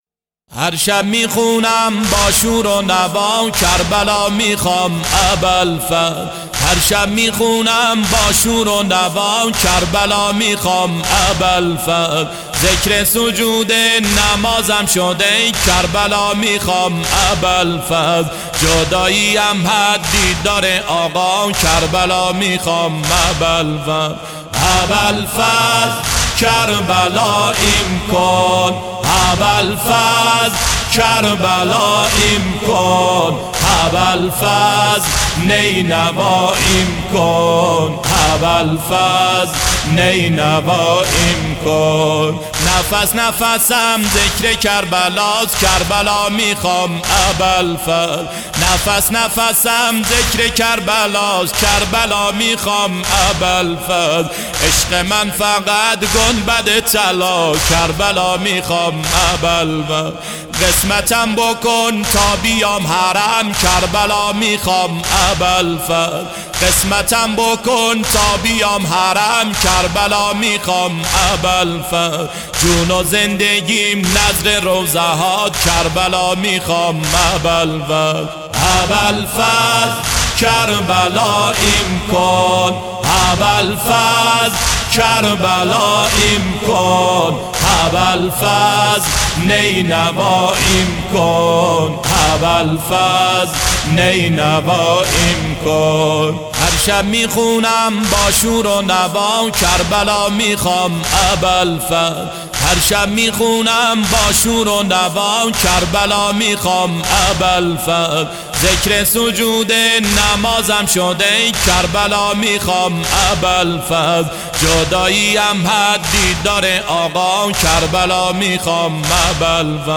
نوحه ابوالفضل کربلایم کن